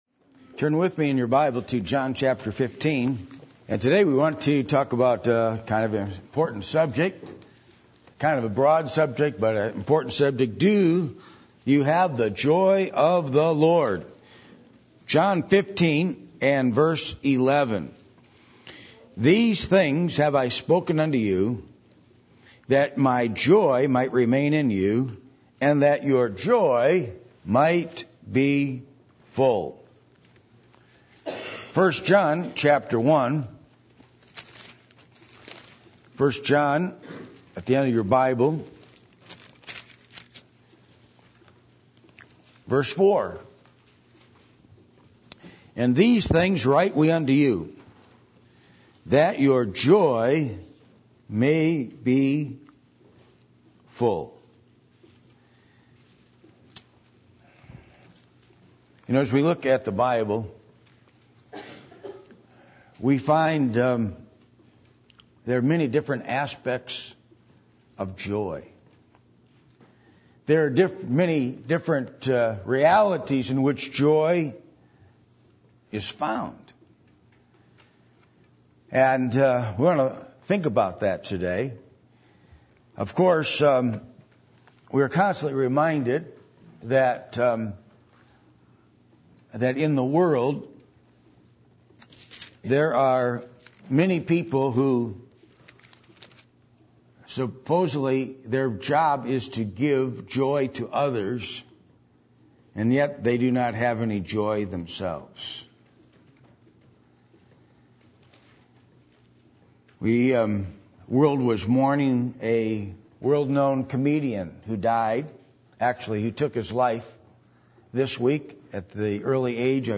John 15:11-12 Service Type: Sunday Morning %todo_render% « The Work Of The Great Commission What Will You Attempt For The Lord?